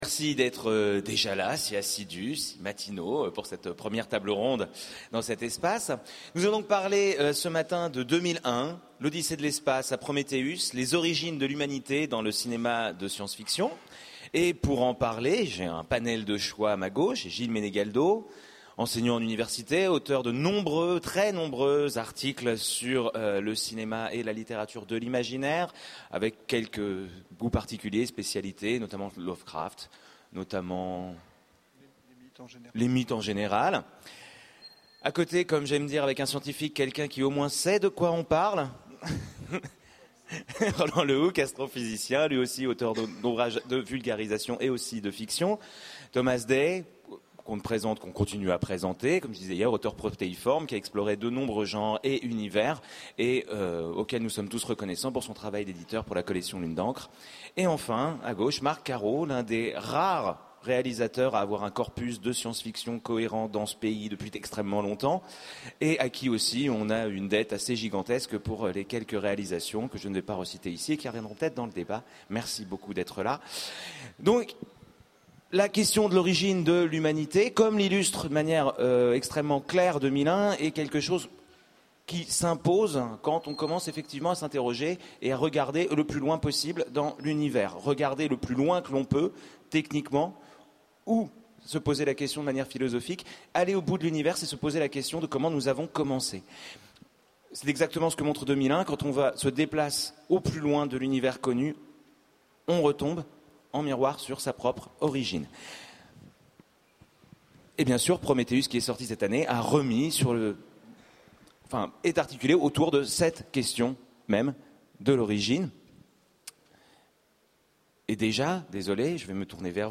Utopiales 12 : Conférence De 2001, l’odyssée de l’espace à Prometheus
Conférence